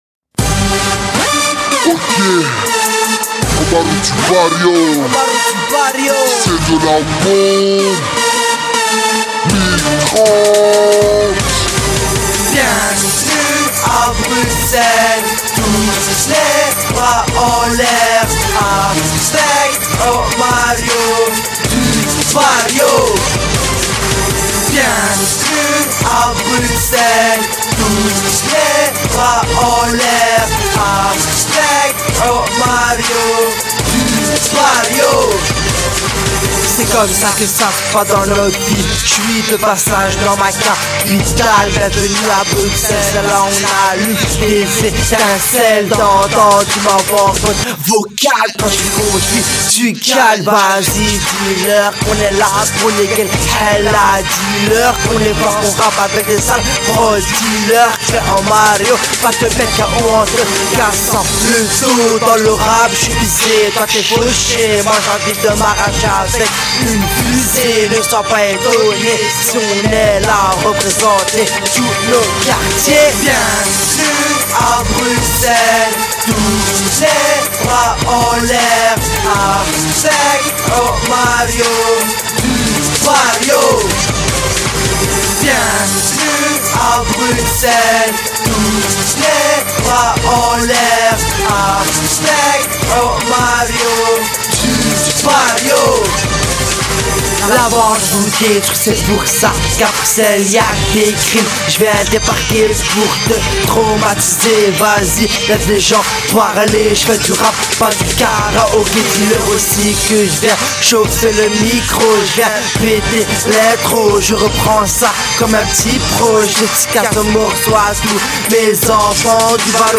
anders Rap